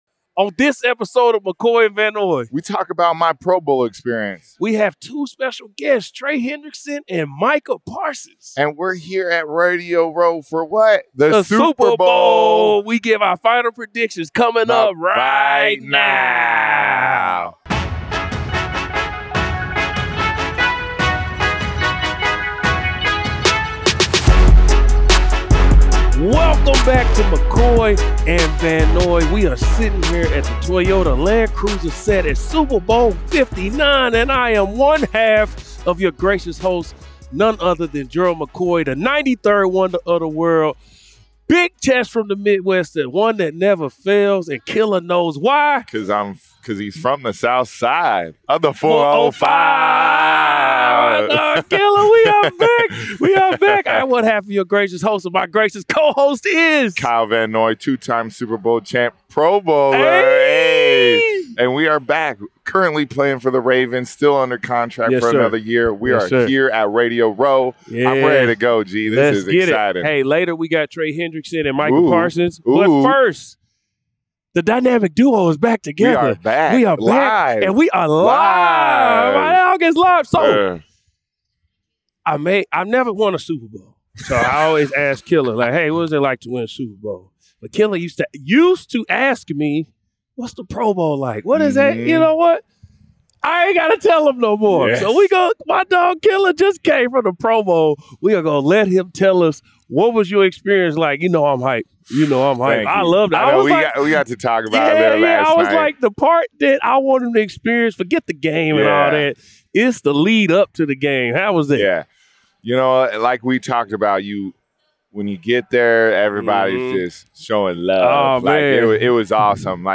Before Super Bowl LIX, Kyle Van Noy and Gerald McCoy are joined by NFL stars Micah Parsons and Trey Hendrickson on the Toyota Land Cruiser set at Super Bowl Radio Row in New Orleans. At the top of the show, McCoy and Van Noy share their final predictions for the Super Bowl and share their thoughts on Jimmy Butler being traded to the Golden State Warriors.